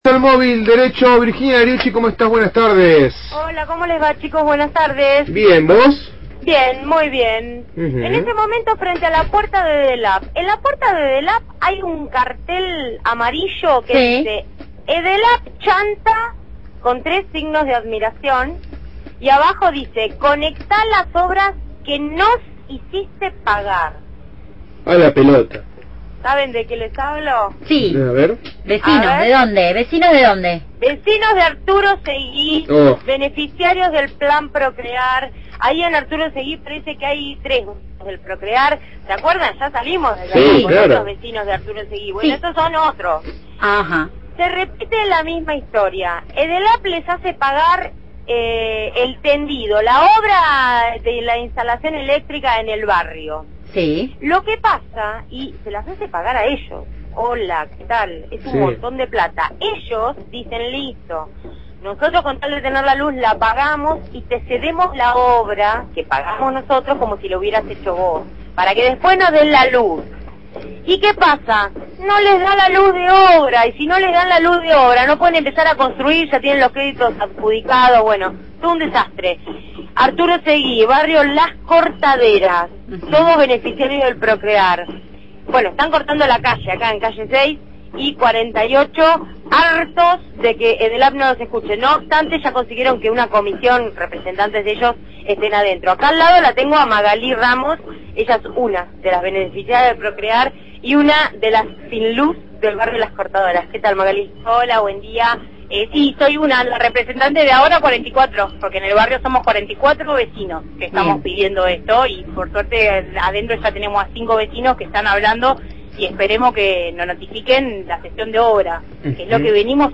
Entrevista a Rocío Giaccone, diputada bonaerense del FPV.